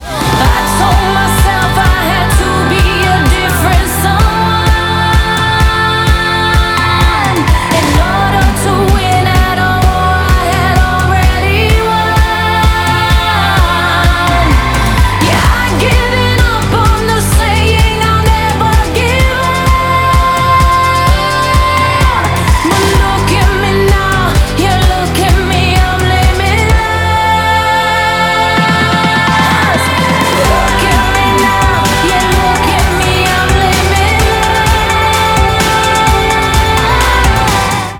• Качество: 128, Stereo
поп
громкие
женский вокал
мотивирующие
саундтреки